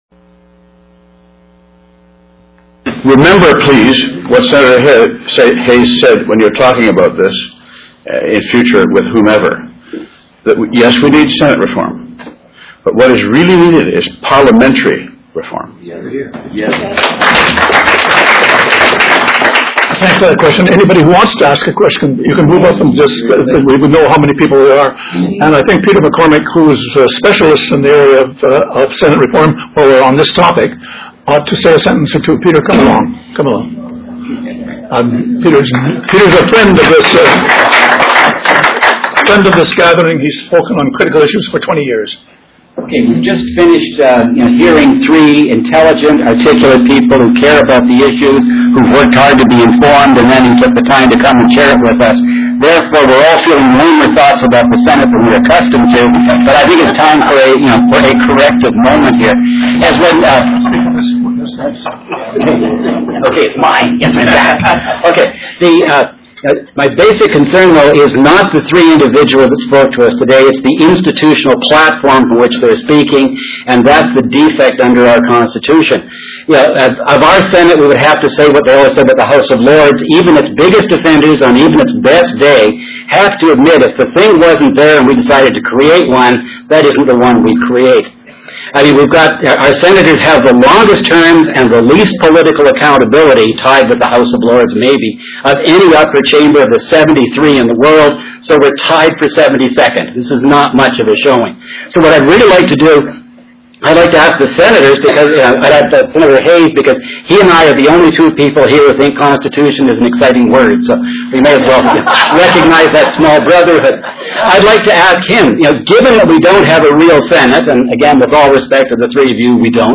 For the first time in the history of SACPA, we are pleased to inform you that our speakers for Thursday,, October 12, 2006 will be three members of the Canadian Senate: SENATOR TOMMY BANKS (Edmonton), SENATOR JOYCE FAIRBAIRN(Lethbridge) AND SENATOR DAN HAYS (Calgary). SENATOR BANKS will address current issues regarding the environment with a special focus on water issues. SENATOR FAIRBAIRN will focus her message on agriculture but will also address literacy. SENATOR HAYS will speak on Senate reform.